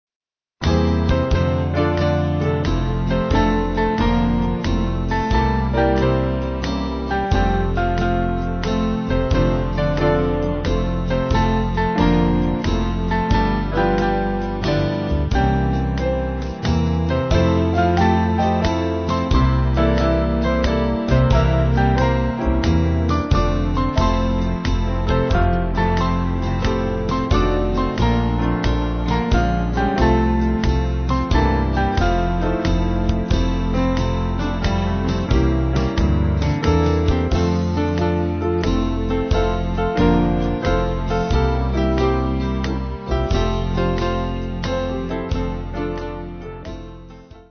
Swing Band
(CM)   4/F-Gb